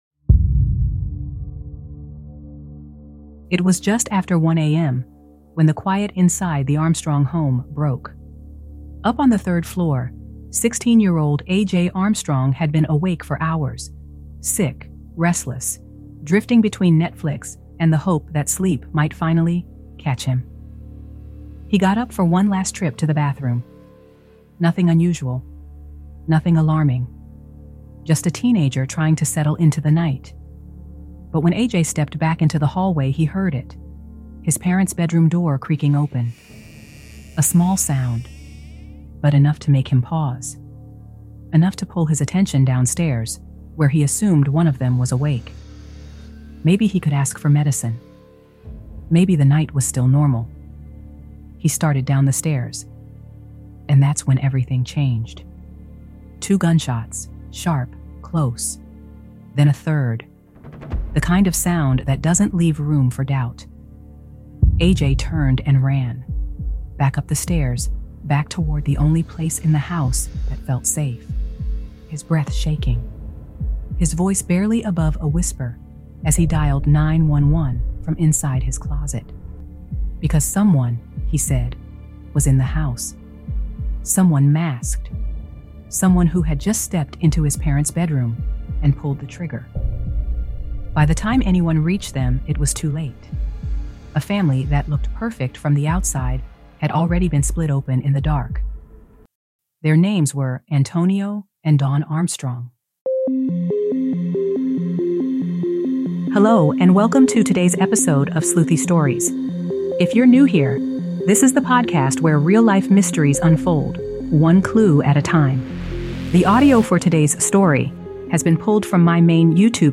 The audio is pulled from our YouTube channel and remastered for an immersive podcast experience, but if you’d like to see the visuals that accompany these cases, you can find them linked in every episode.